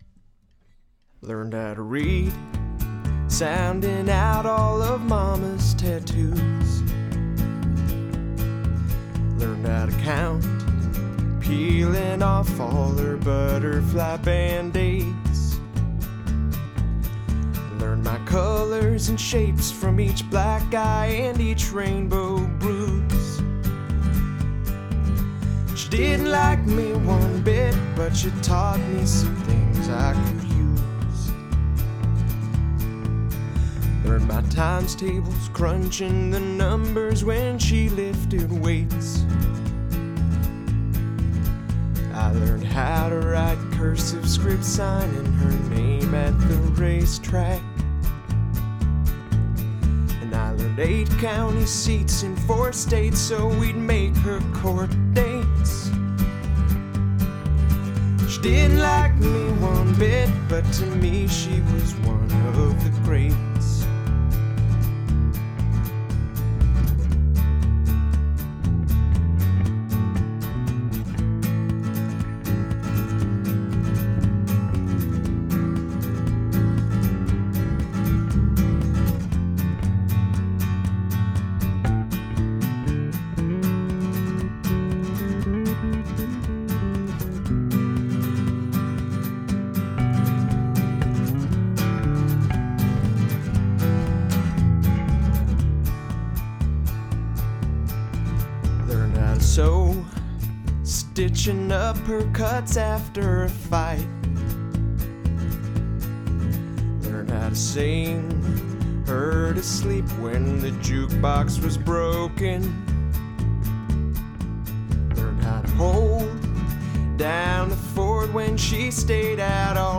Taxi Demo